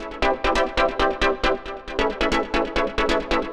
Index of /musicradar/future-rave-samples/136bpm
FR_Minee_136-C.wav